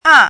怎么读
à